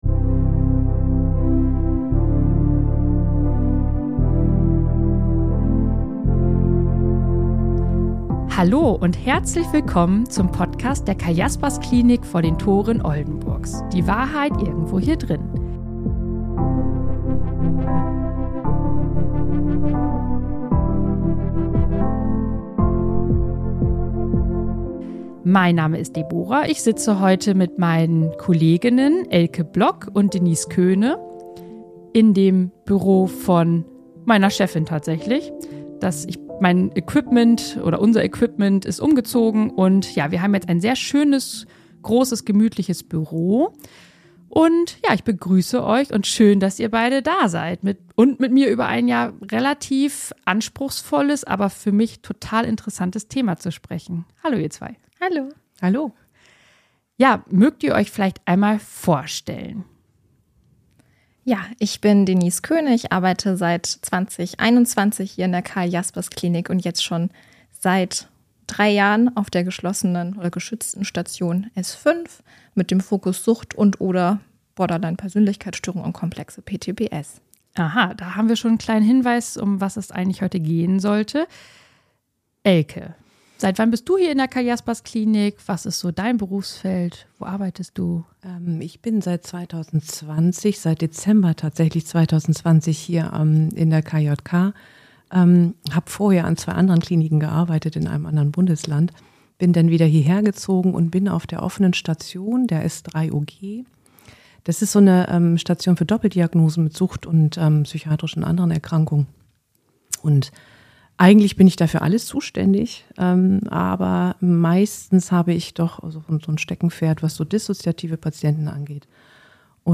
#46 KOMPLEXE POSTTRAUMATISCHE BELASTUNGSSTÖRUNG (KPTBS) – Experten-Talk ~ Die Wahrheit Irgendwo Hier Drinnen Podcast